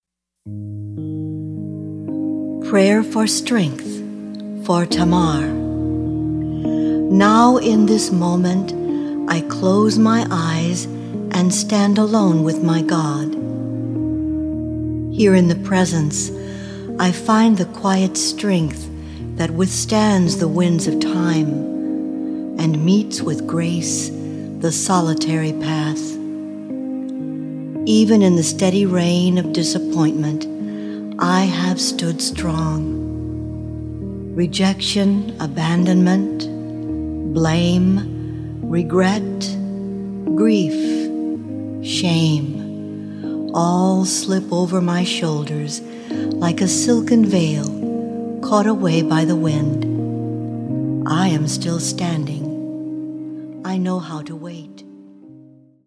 This double CD includes a 3 minute meditation and a song for each of the 12 powers interpreted through the Divine Feminine.